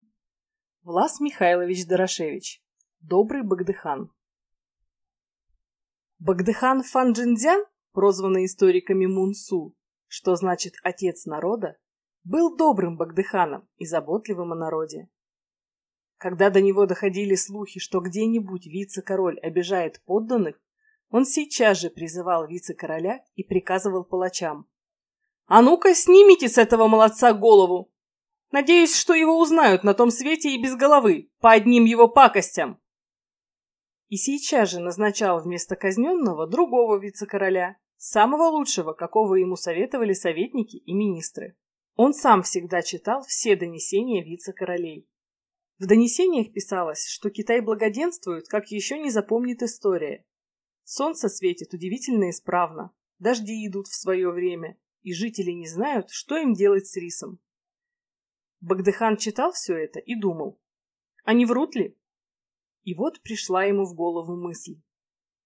Аудиокнига Добрый богдыхан | Библиотека аудиокниг